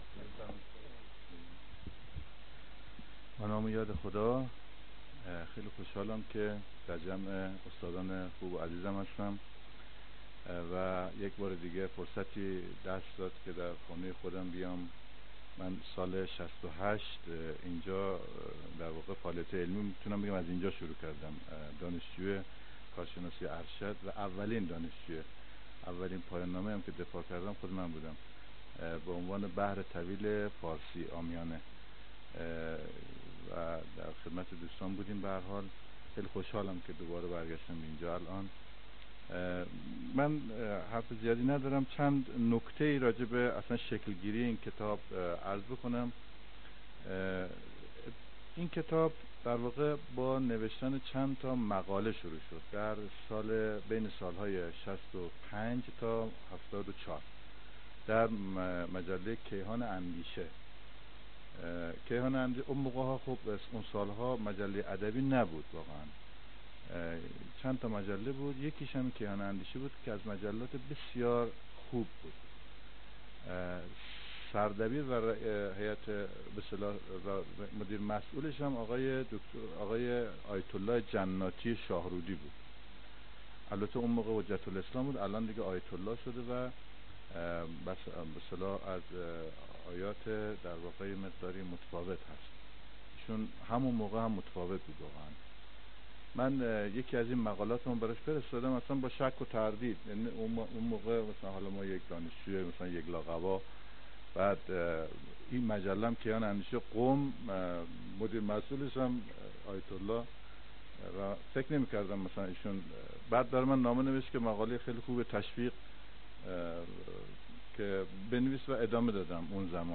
پژوهشکده زبان و ادبیات با همکاری انجمن نقد برگزار می کند: